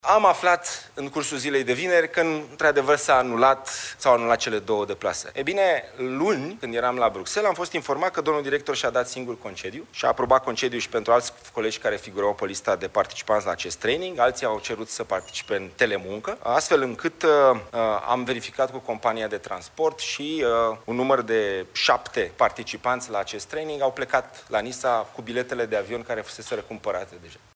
Dragoș Pîslaru, ministrul Investițiilor și Proiectelor Europene: „Domnul director și-a dat singur concediu și a aprobat concediu și pentru alți colegi care figurau pe lista de participanți la acest training”